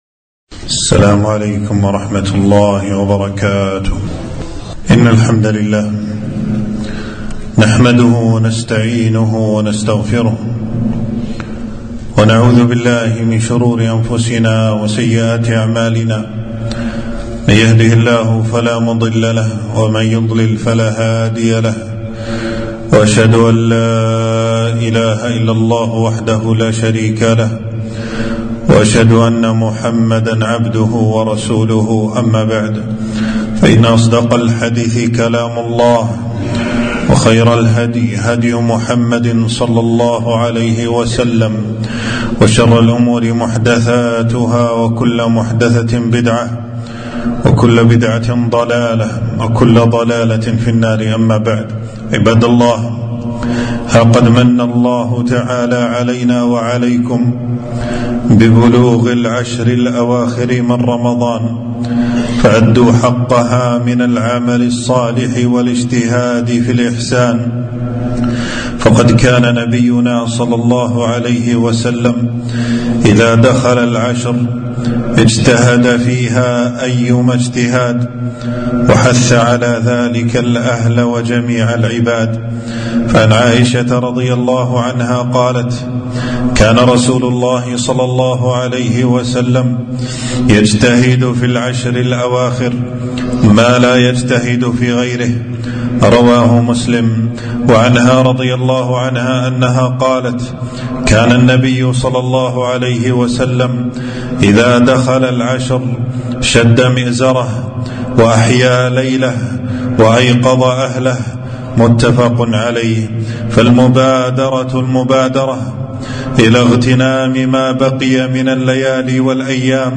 خطبة - ليلةُ القدر خير من ألف شهر